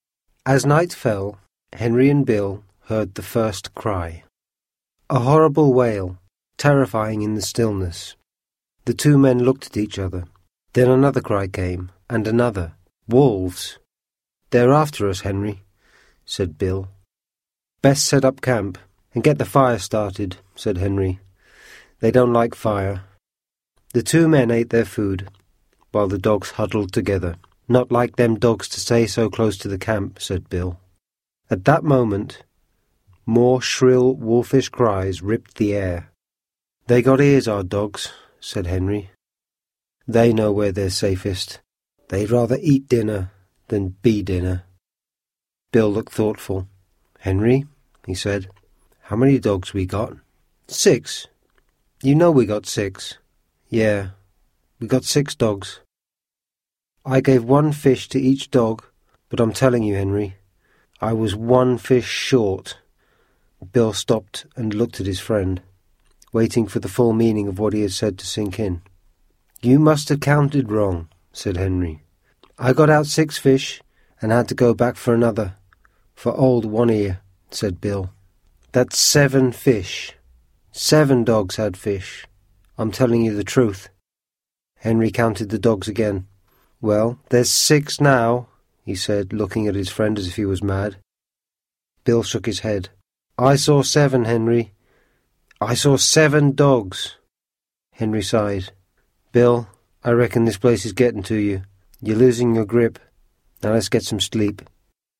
Audiokniha White Fang audiokniha obsahuje slavné dílo spisovatele Jacka Londona, které v angličtině předčítá rodilý mluvčí.
Ukázka z knihy